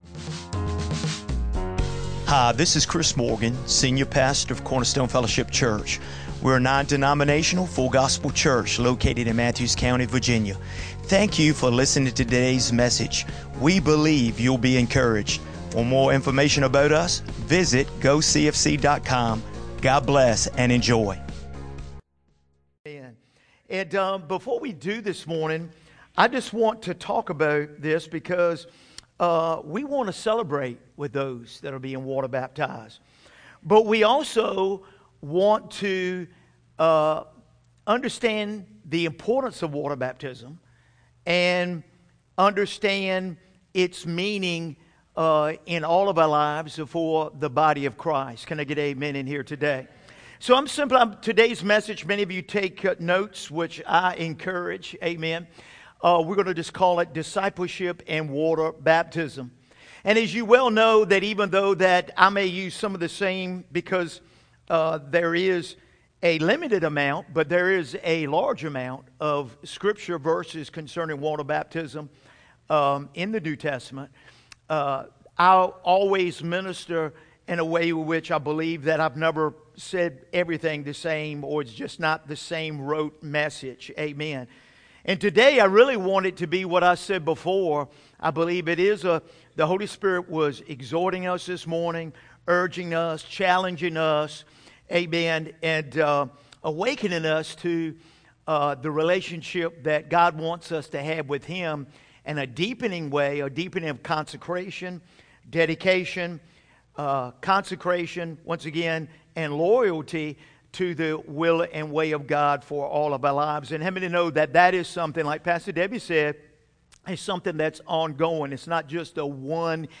2024 Sunday Morning In this powerful message